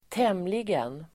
Ladda ner uttalet
Uttal: [²t'em:ligen]